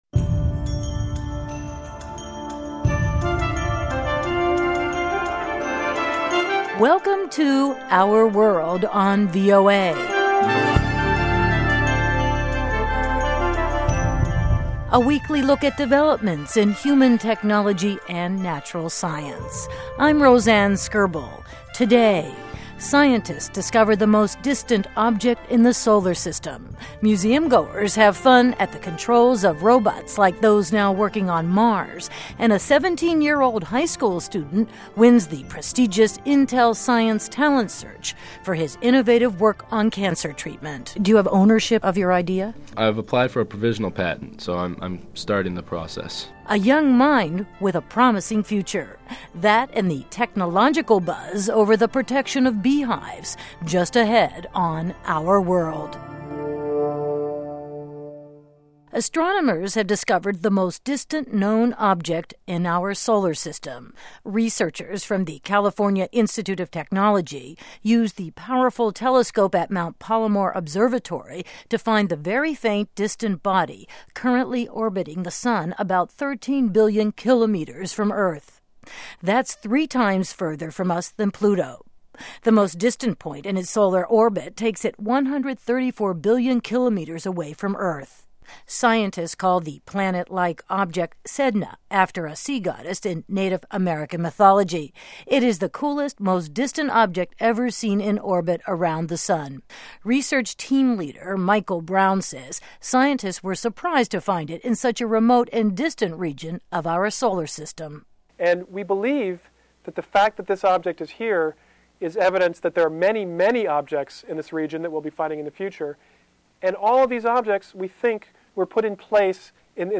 Our World radio interview, Voice of America
Interview begins 5 minutes in to the show.